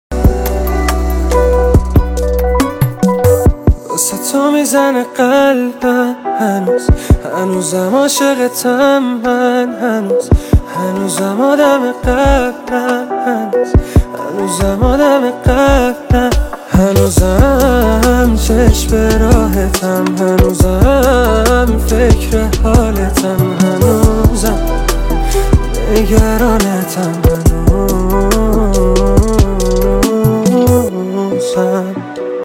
غمگین
عاشقانه و غمگین